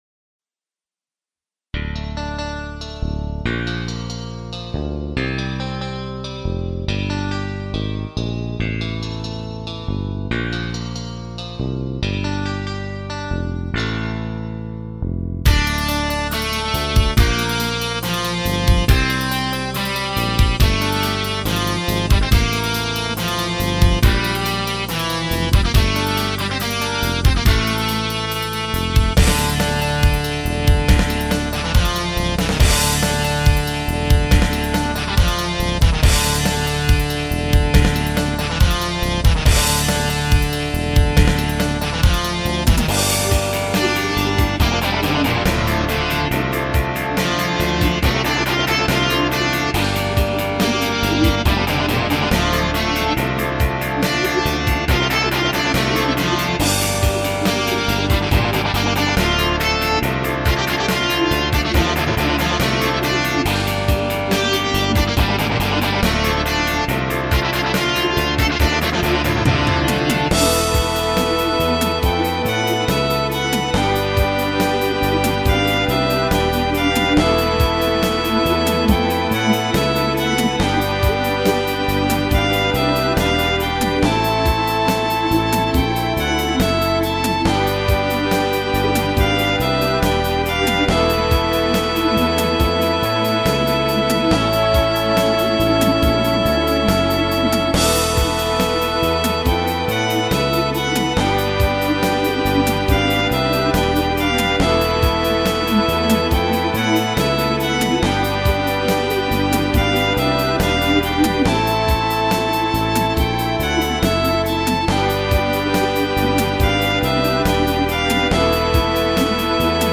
ROCK MUSIC ; BALLADS